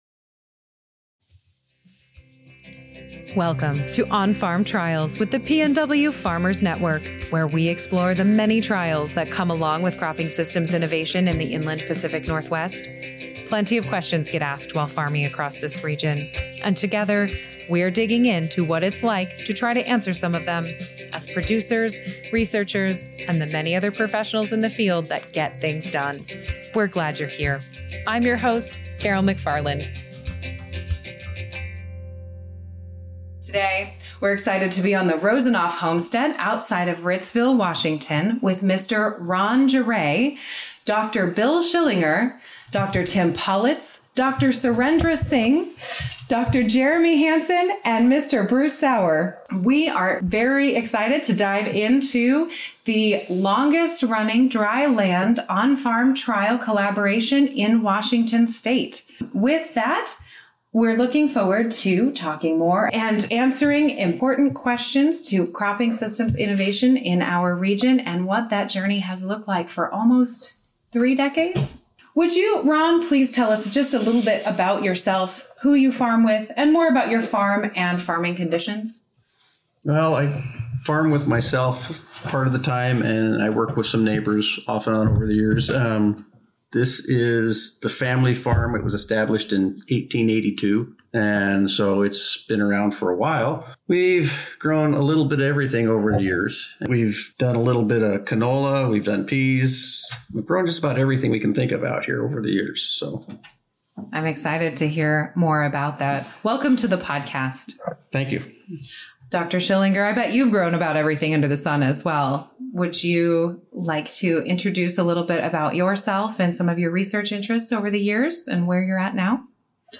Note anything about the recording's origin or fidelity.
This episode of On-Farm Trials comes to you from the Rosanoff Homestead outside of Ritzville, WA